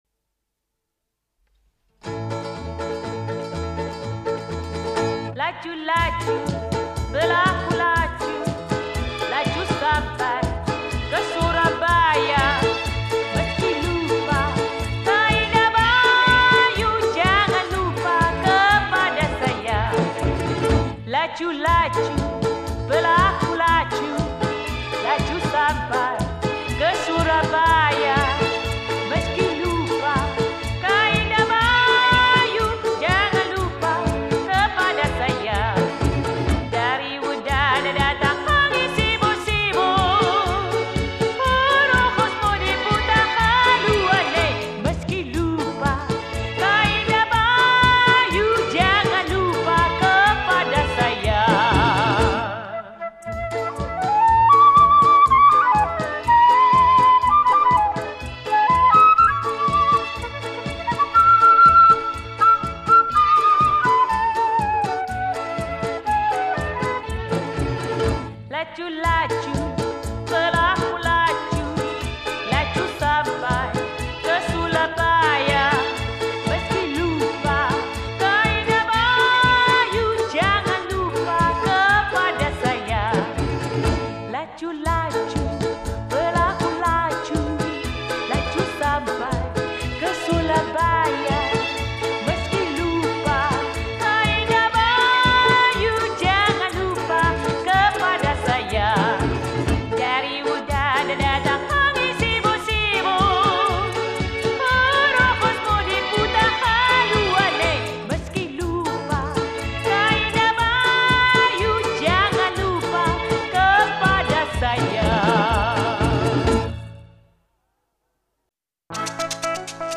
印度尼西亚民歌
1981年版+重新配器版